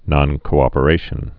(nŏnkō-ŏpə-rāshən)